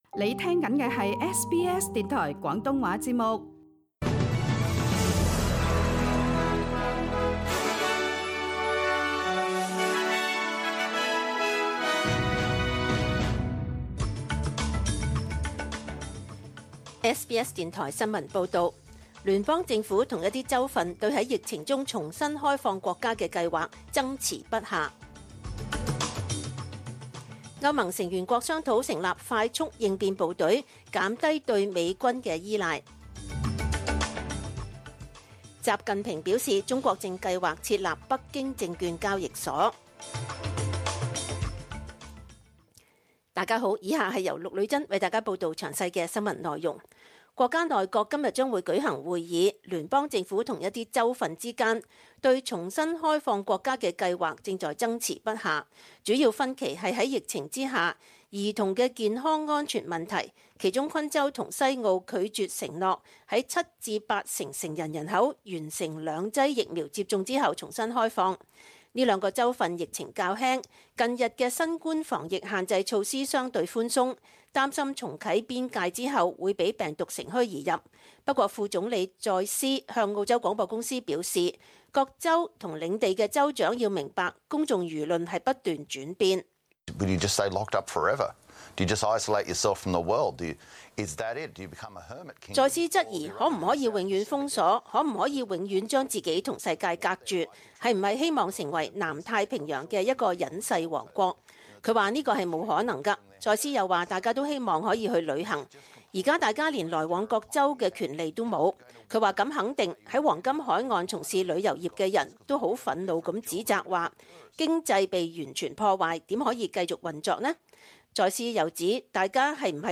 SBS 中文新聞（九月三日）